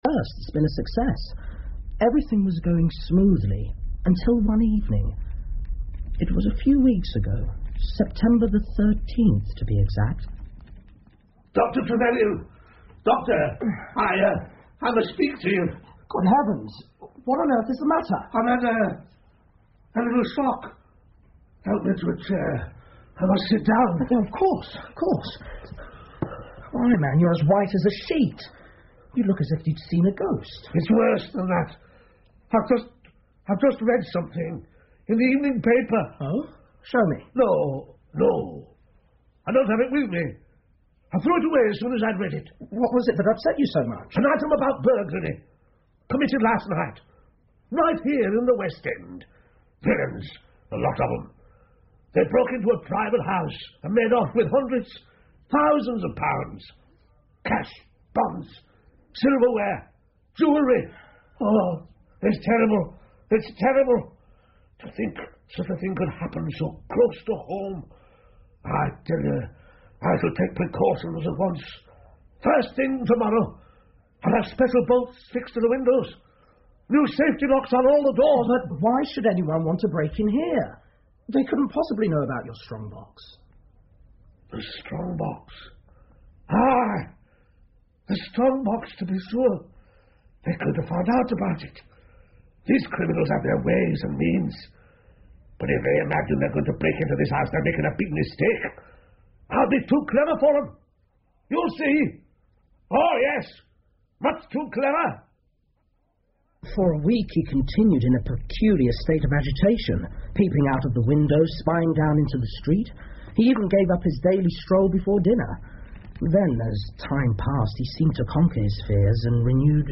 福尔摩斯广播剧 The Resident Patient 3 听力文件下载—在线英语听力室